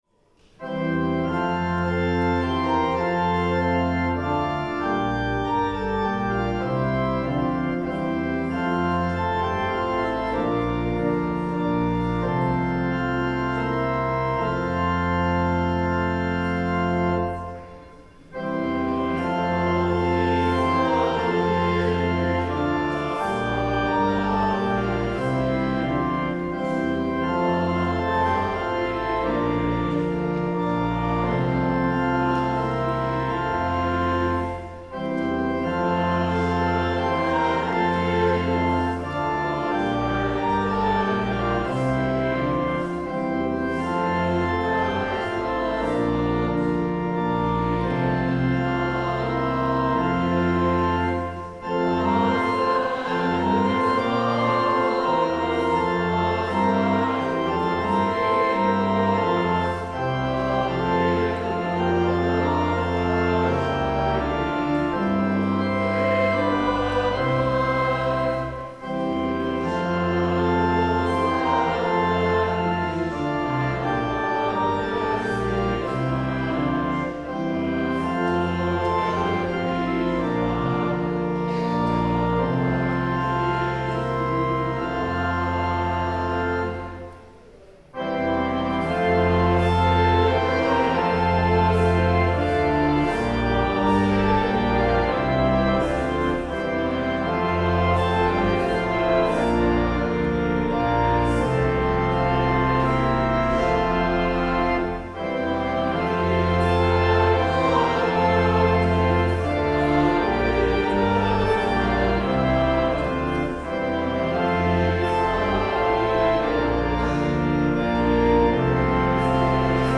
Drum
The Lord’s Prayer (sung)
ABOUT THE MUSIC: This Sunday at St. John’s our Choir sang Ispiciwin , a work by Cree composer Andrew Balfour, whose music brings together choral traditions and Indigenous stories in a way that invites reflection, reconciliation, and hope.